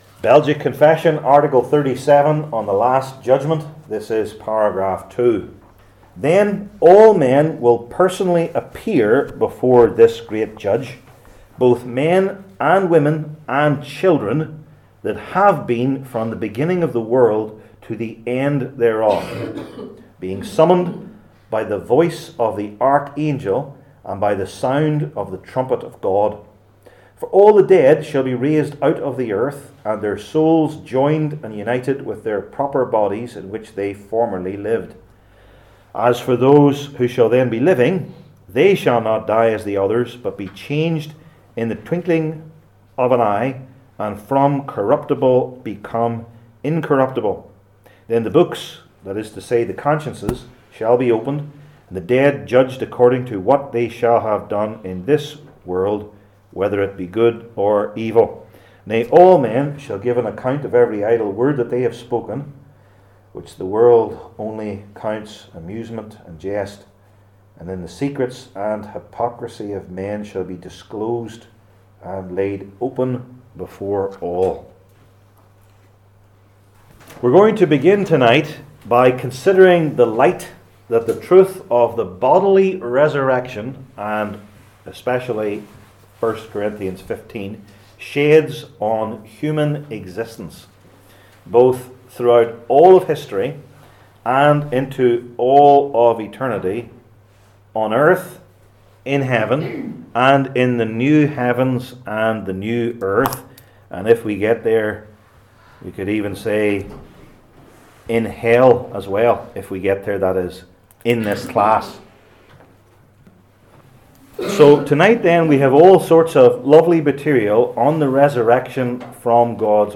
I Corinthians 15:42-53 Service Type: Belgic Confession Classes THE LAST JUDGMENT …